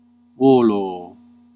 Ääntäminen
IPA : /miːn/